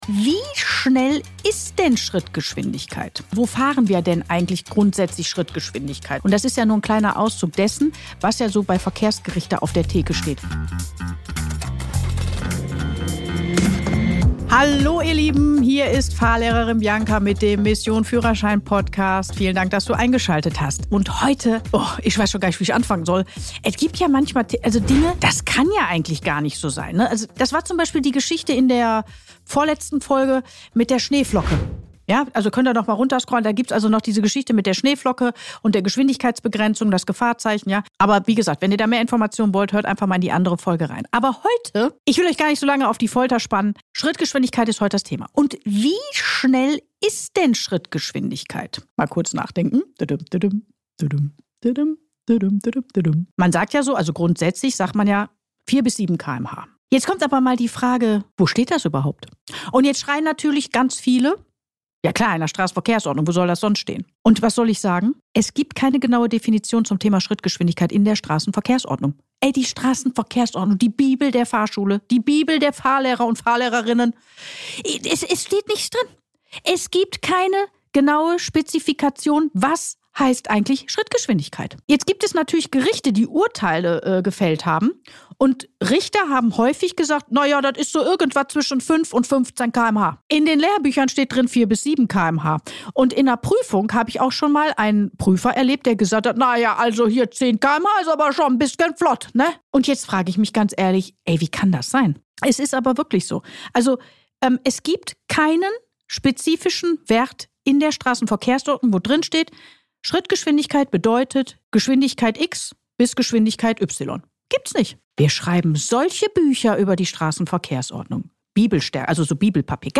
In dieser Solo-Folge spreche ich über ein Thema, das im ersten